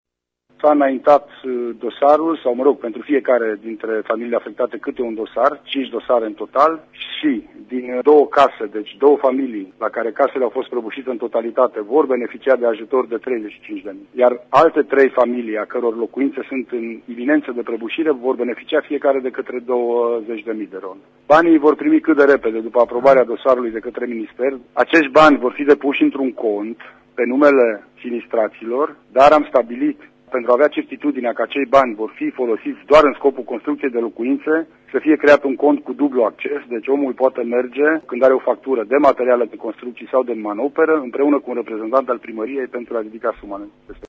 Prefectul de Mureș, Lucian Goga: